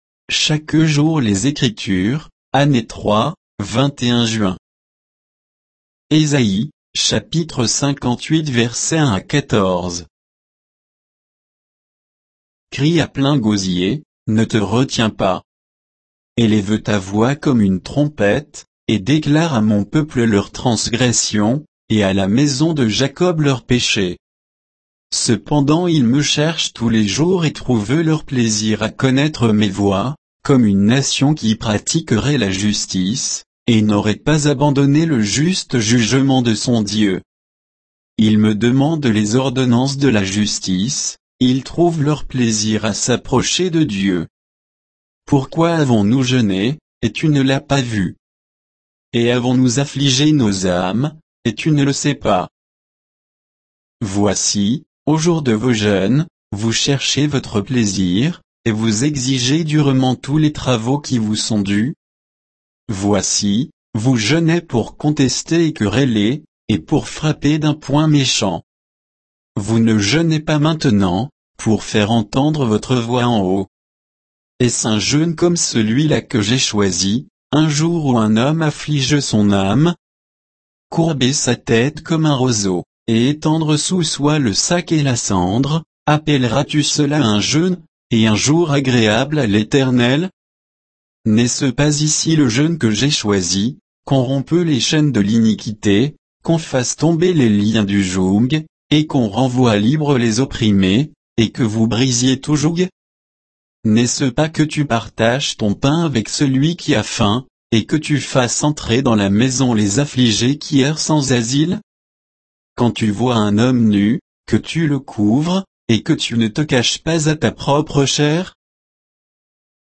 Méditation quoditienne de Chaque jour les Écritures sur Ésaïe 58, 1 à 14